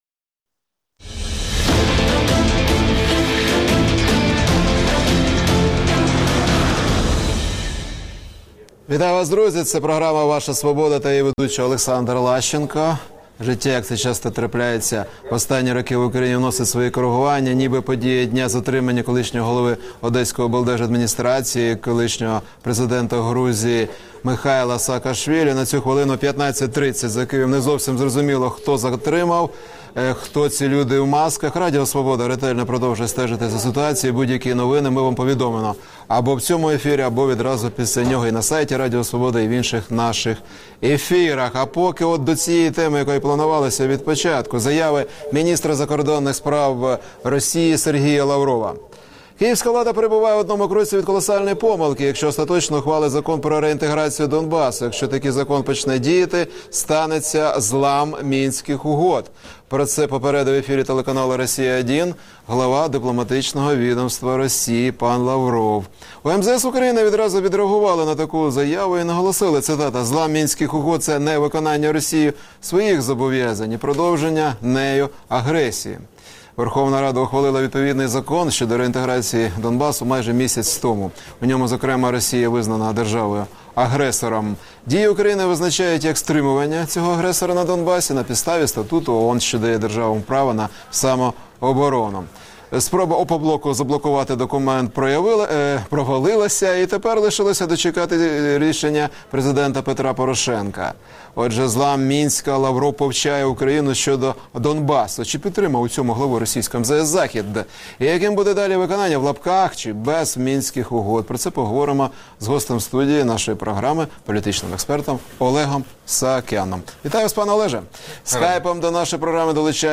Володимир Огризко, екс-міністр закордонних справ України